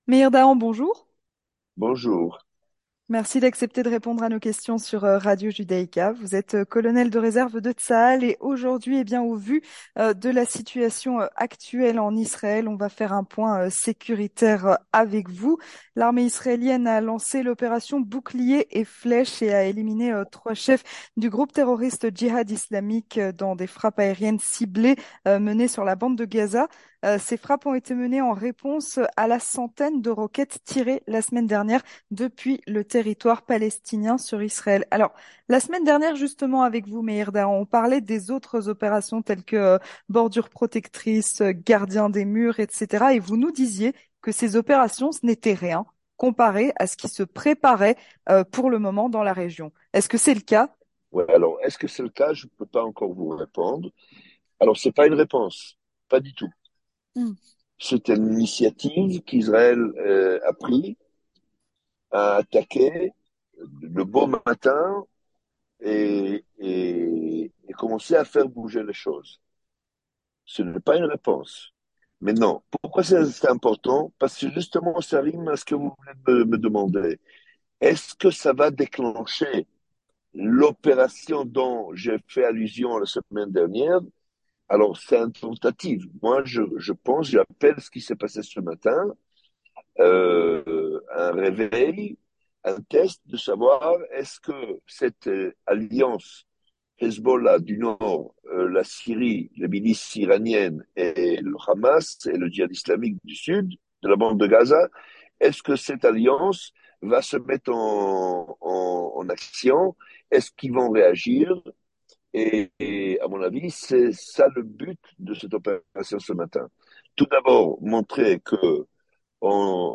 Entretien du 18h - Point sur la situation sécuritaire en Israël